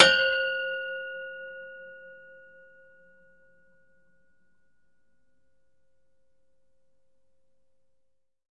命中 金属 " 命中 金属 篮球圈管 02
描述：击中撑起篮筐的杆子，用木杆。 用Tascam DR40录制。
Tag: 金属 音调 篮球 命中 冲击 木材 打击乐器 金属 体育 笔记 冲击 体育 俯仰baskbetball 碰撞 振铃音